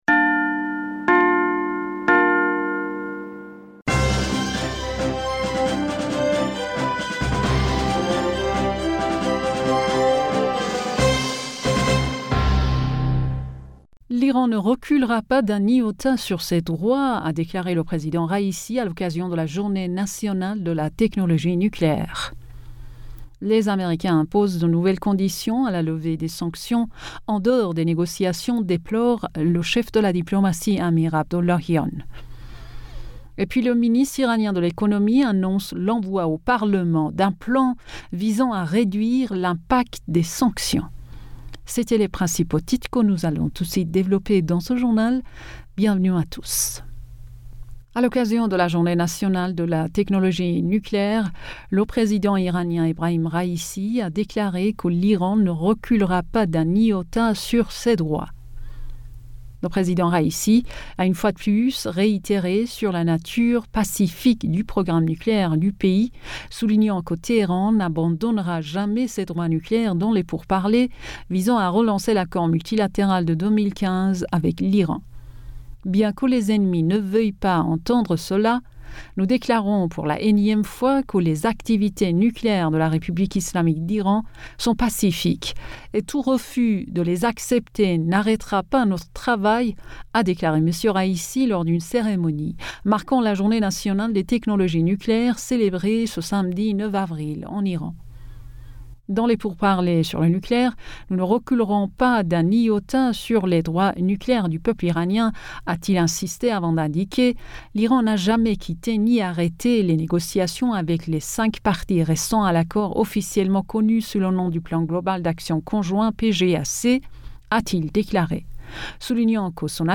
Bulletin d'information Du 10 Avril 2022